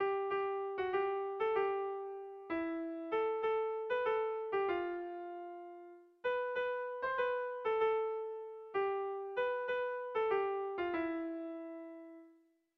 Sentimenduzkoa
Lauko txikia (hg) / Bi puntuko txikia (ip)
AB